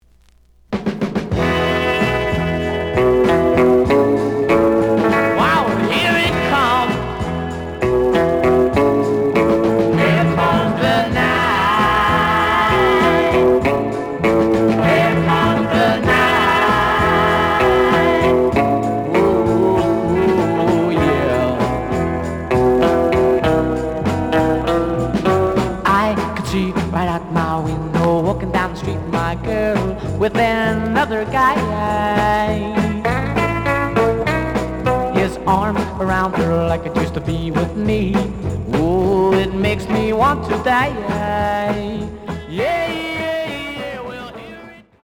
The audio sample is recorded from the actual item.
●Genre: Rock / Pop
Slight noise on A side.